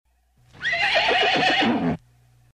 Звуки ржания лошади
Звук ржания кобылы